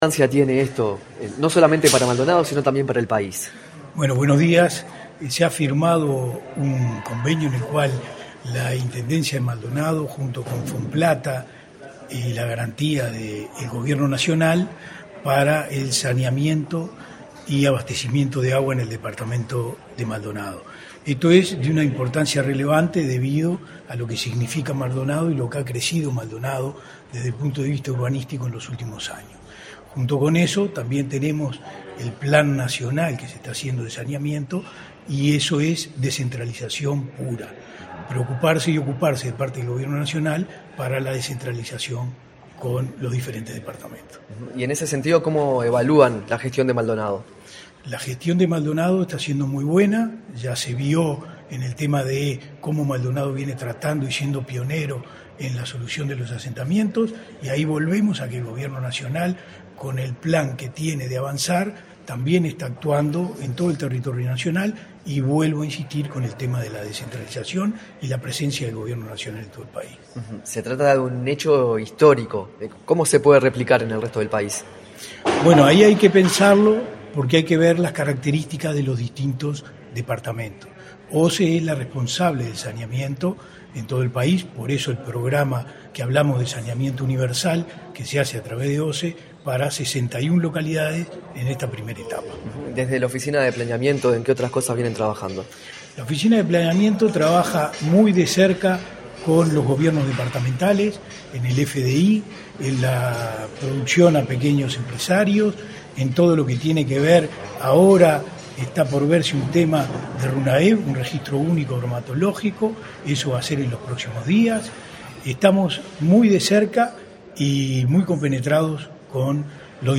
Declaraciones a la prensa del director de la OPP, Fernando Blanco
Tras el evento, el director de la Oficina de Planeamiento y Presupuesto (OPP), Fernando Blanco, realizó declaraciones a la prensa.